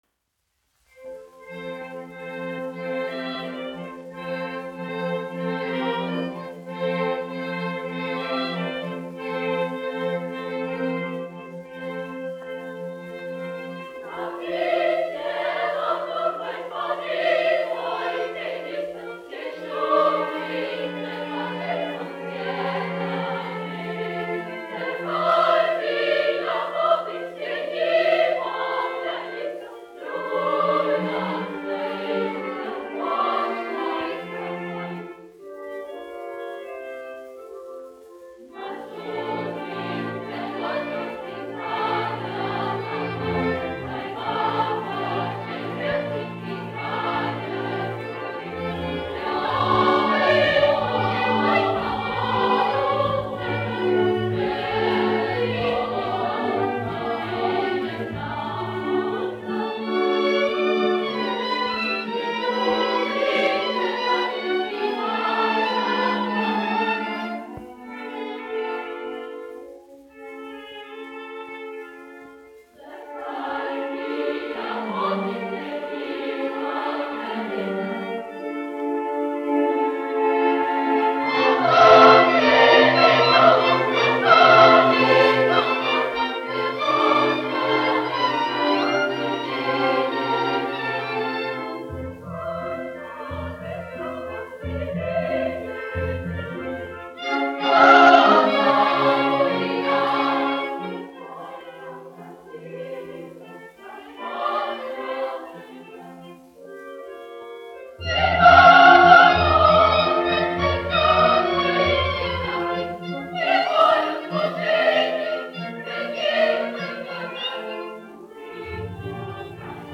Latvijas Nacionālā opera. Koris, izpildītājs
Emil Cooper, diriģents
1 skpl. : analogs, 78 apgr/min, mono ; 30 cm.
Operas--Fragmenti
Latvijas vēsturiskie šellaka skaņuplašu ieraksti (Kolekcija)